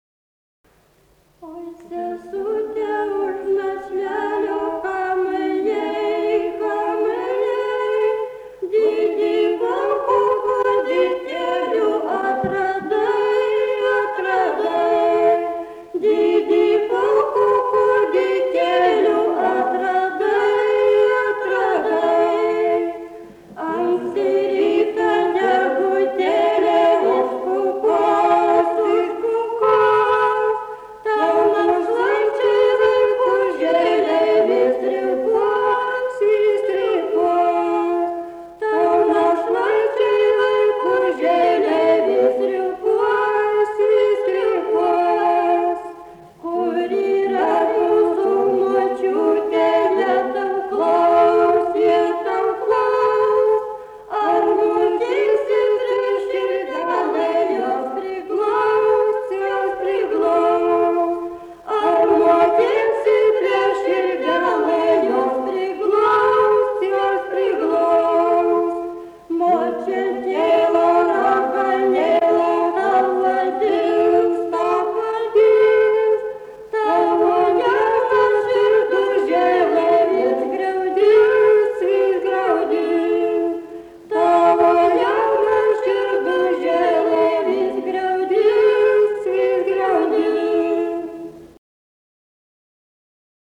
smulkieji žanrai
Erdvinė aprėptis Vabalninkas
Atlikimo pubūdis vokalinis